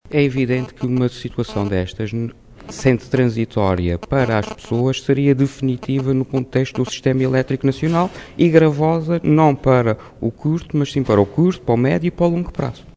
Vítor Fraga, em declarações aos jornalistas após ter sido ouvido na Comissão de Economia do parlamento açoriano, explicou que “a medida que o PCP propõe reduz drasticamente as transferências da Entidade Reguladora do Setor Energético (ERSE) para a EDA e isto significaria que iria agravar a fatura elétrica das pessoas”.